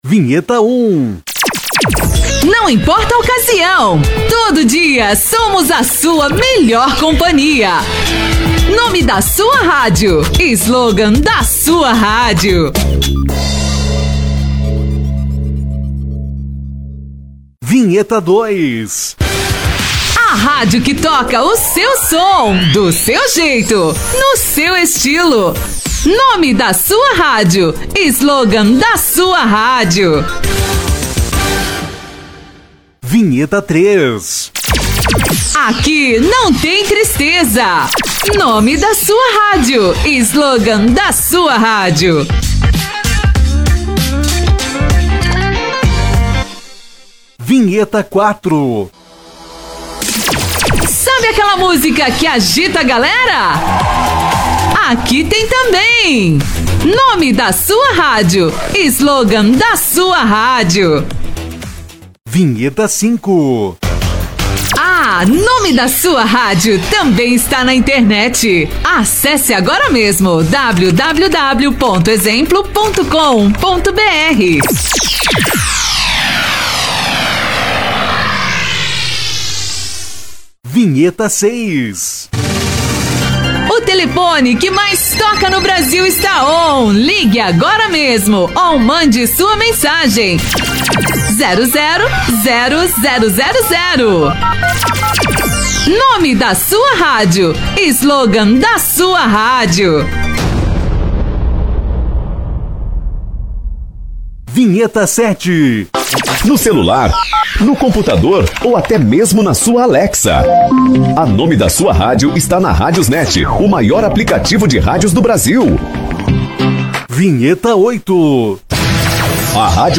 11 Vinhetas pré-montadas de rádio com o nome da sua rádio, slogan, site, telefone, Whatsapp e Rádiosnet
– Nome da rádio, slogan, telefone, WhatsApp, cidade e site gravados com locução profissional